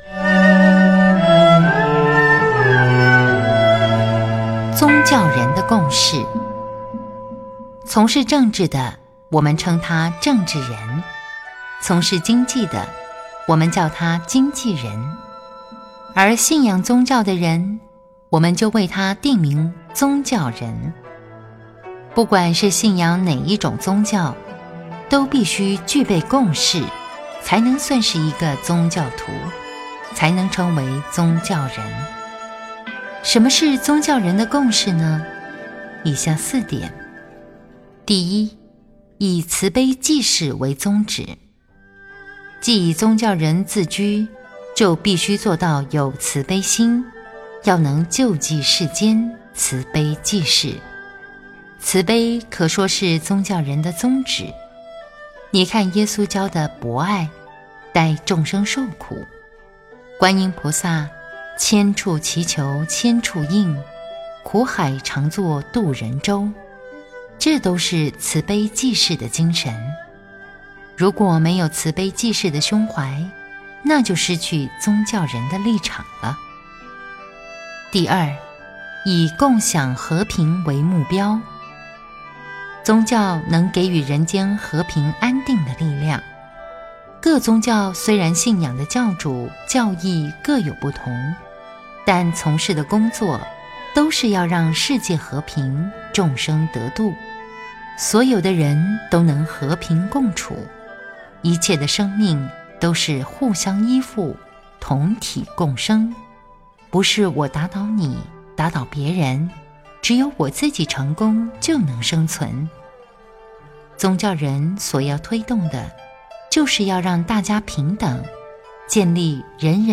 佛音 冥想 佛教音乐 返回列表 上一篇： 17.